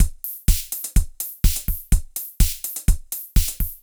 IBI Beat - Mix 2.wav